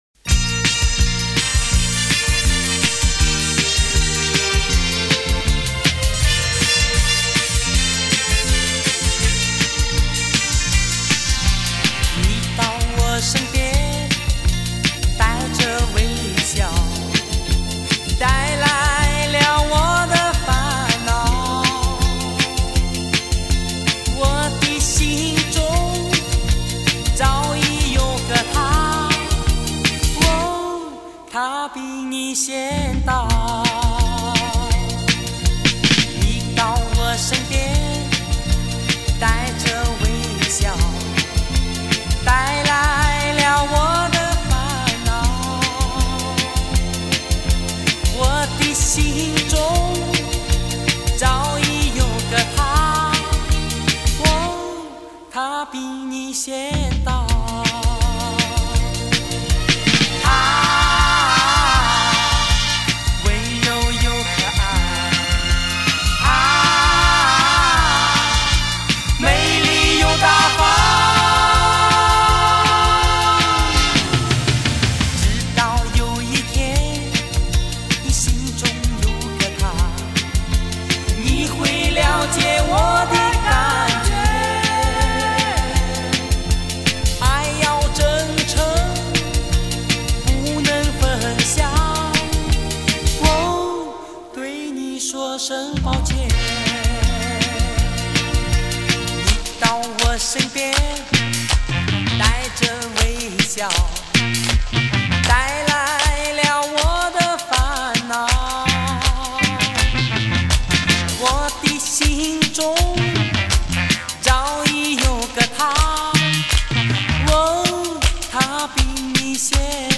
音乐类型：华语流行/男歌手